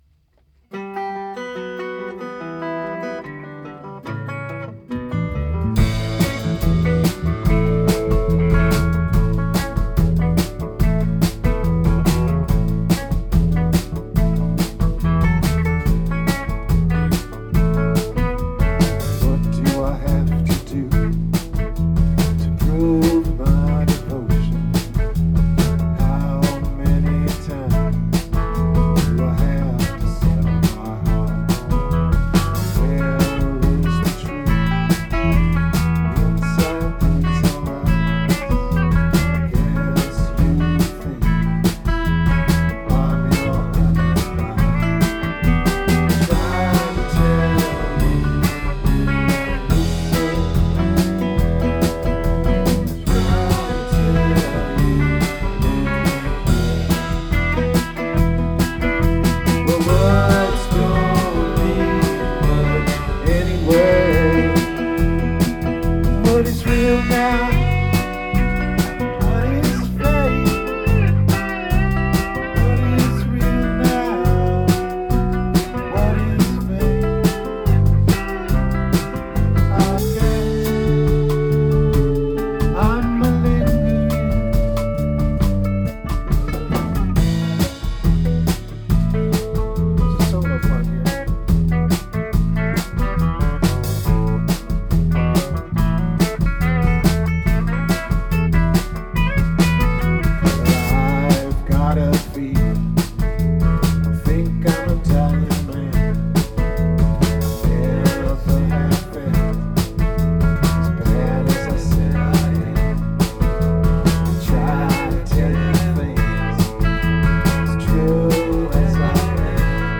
Rehearsals 30.7.2013